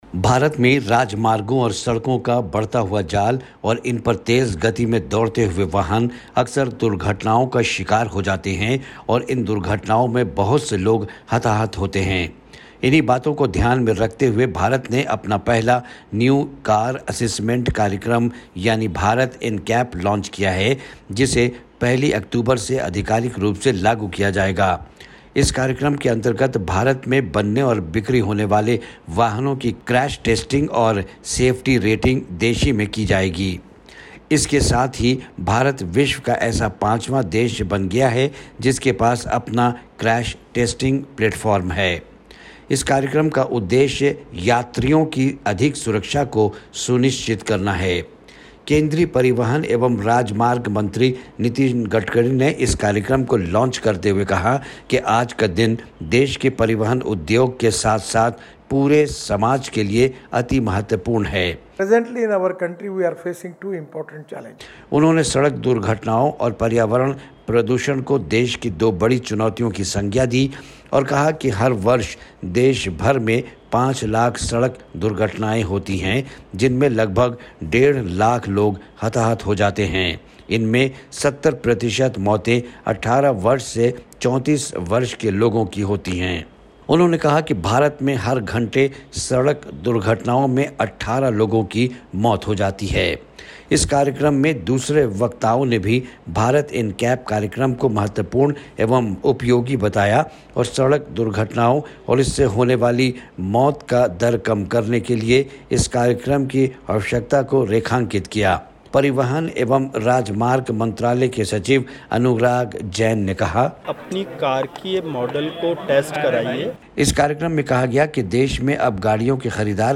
भारत में कार क्रैश की स्टार रेटिंग शुरू, रिपोर्ट